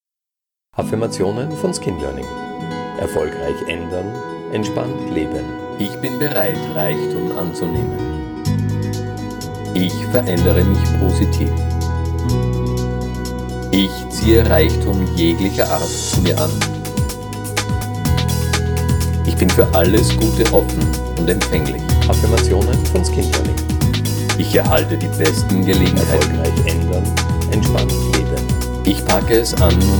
• 1 x Sprache, Subliminal und mit Musik (das “BESTE”) – um Sie emotional zu bewegen
Die Aussage “suBRAINa – erfolgreich ändern – entspannt leben” ist nur in dieser Hörprobe zu hören, auf den Originaldateien scheint diese nicht auf.
P-29-Reichtum-Spr-Sub-Musik.mp3